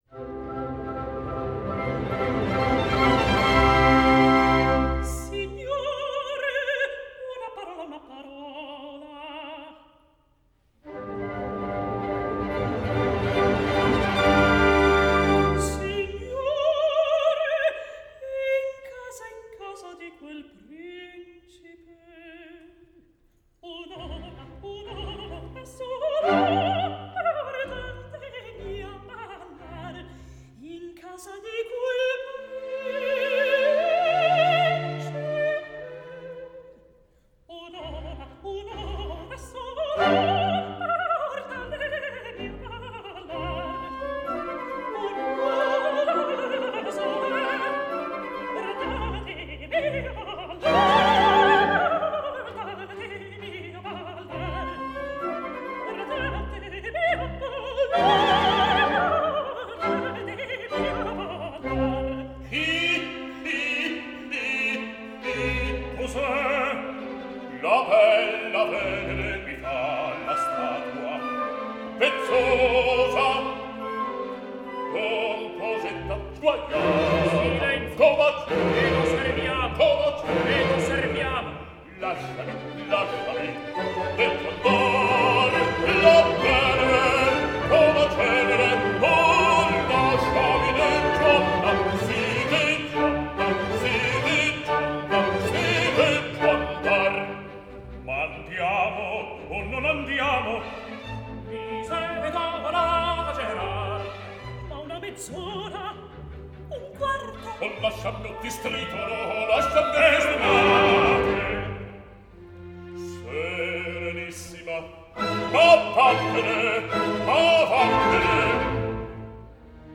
25 августа ушел из жизни итальянский оперный певец бас Энцо Дара
Quintetto